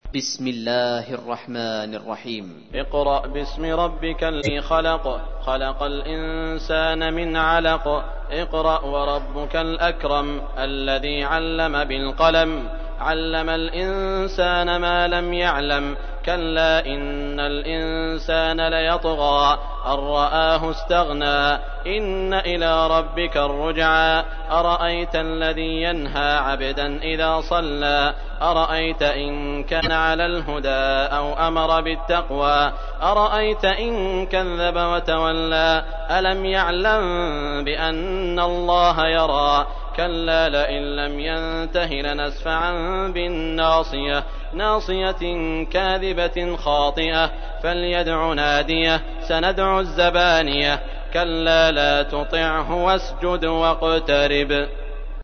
تحميل : 96. سورة العلق / القارئ سعود الشريم / القرآن الكريم / موقع يا حسين